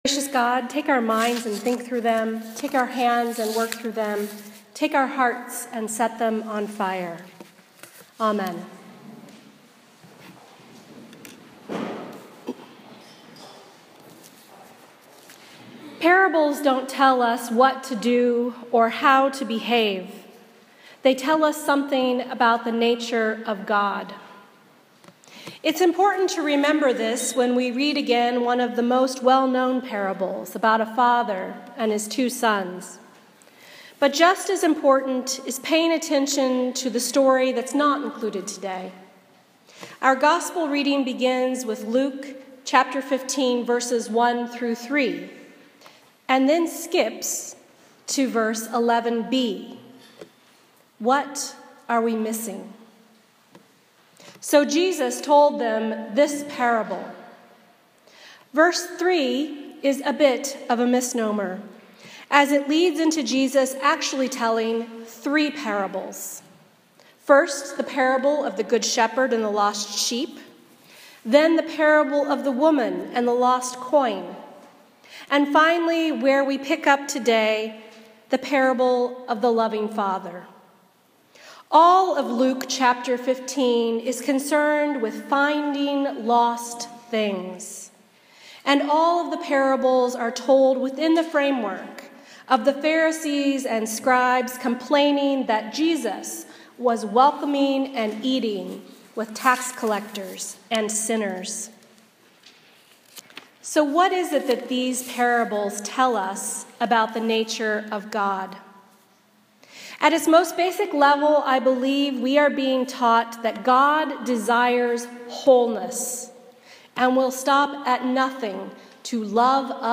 A Sermon for the Fourth Sunday of Lent 2016